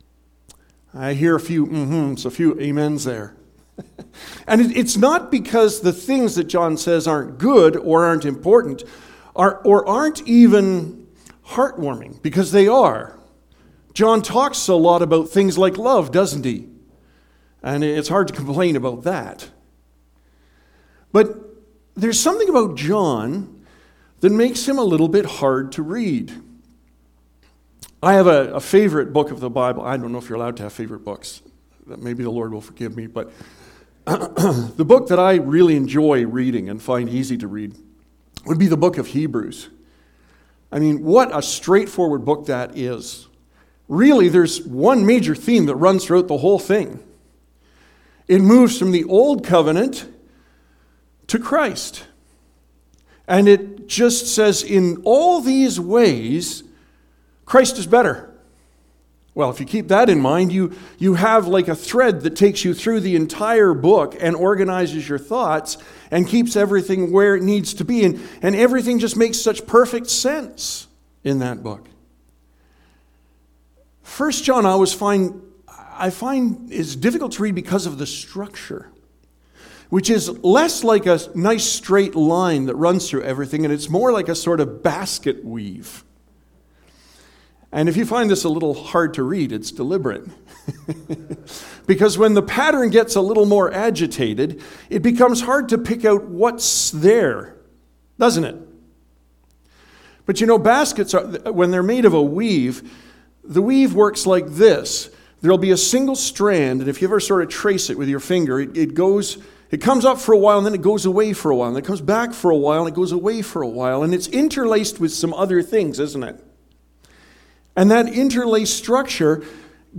Sermon Audio and Video "Do I Truly Believe?"